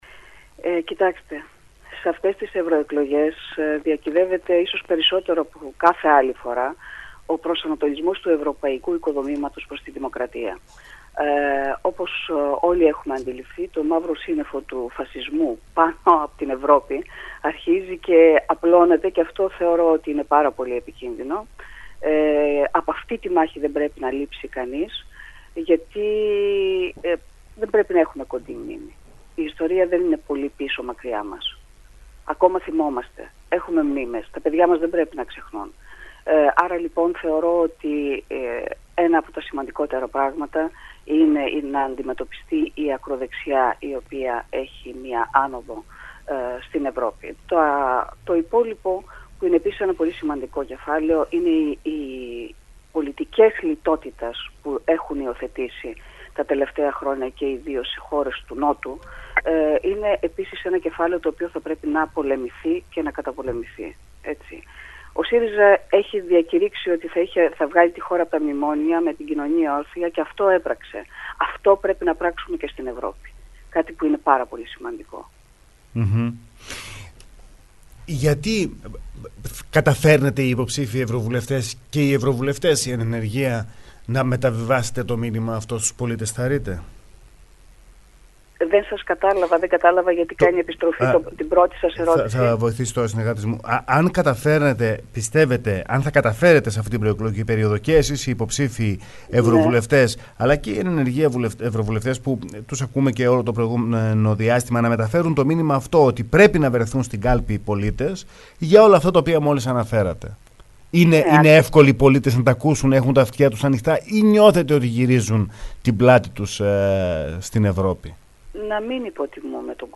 το ραδιόφωνο sferikos99,3.